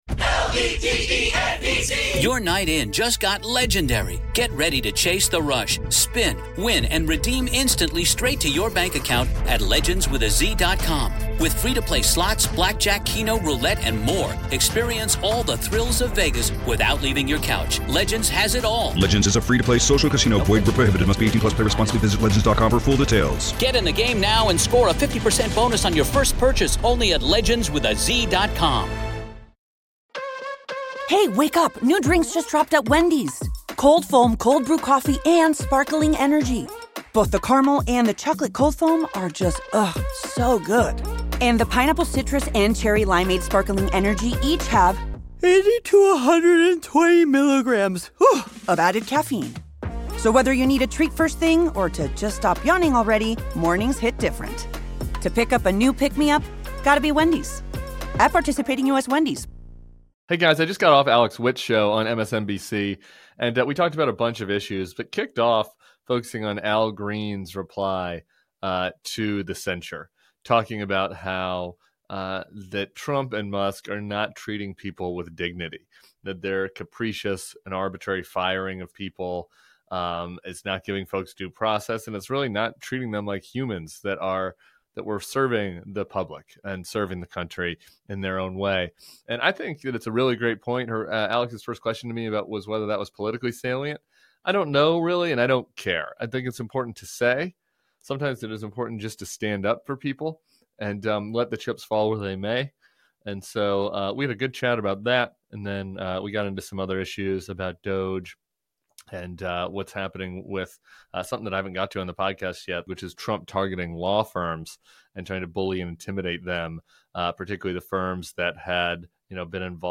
Tim Miller appeared on MSNBC's Alex Witt Reports to discuss the censure of Rep. Al Green, Trump's retribution agenda, and how the courts are handling Trump's abuse of power.